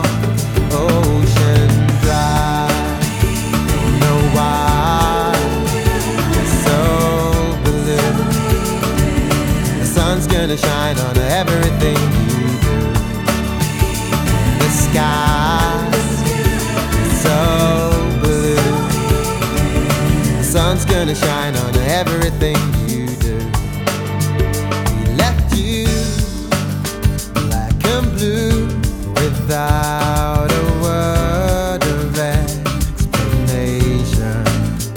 Жанр: Поп музыка / Рок / R&B / Альтернатива / Соул